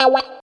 136 GTR 4 -L.wav